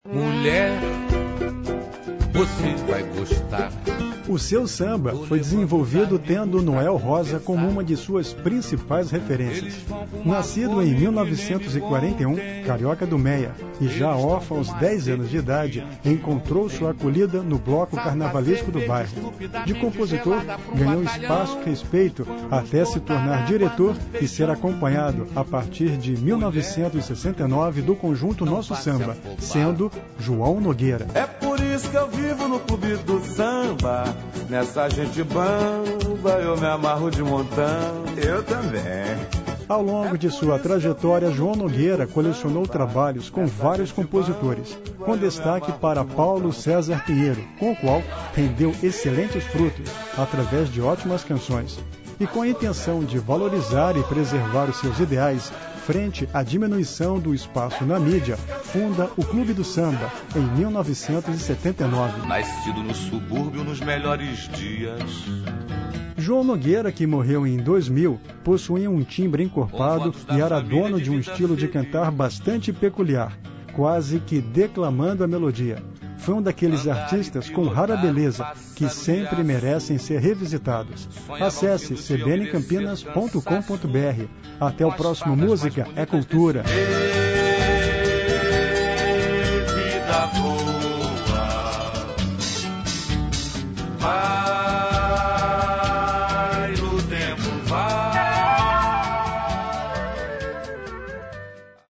João  Nogueira que morreu em 2000,  possuía um   timbre encorpado  e era dono de um estilo de cantar bastante peculiar   quase que declamando  a melodia .